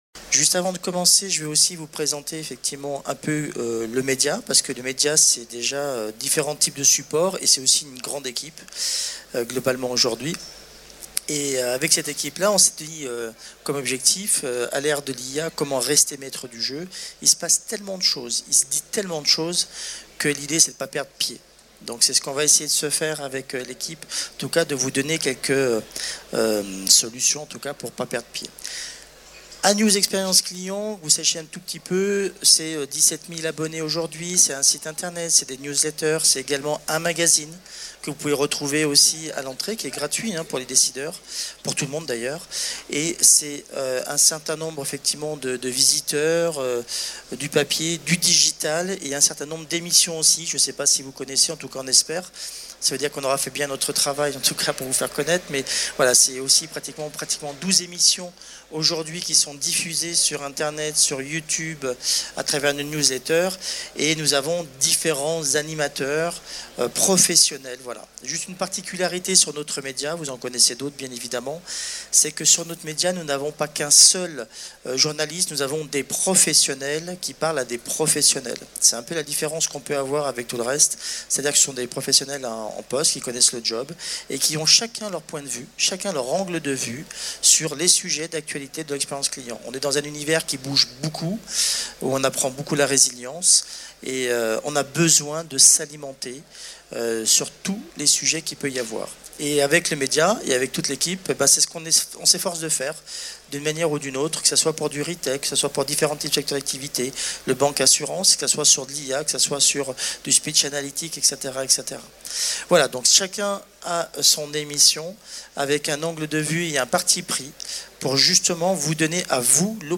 Le collectif d’experts de l’Agora Expérience Client vous propose une masterclass de 50 minutes pour inverser la tendance.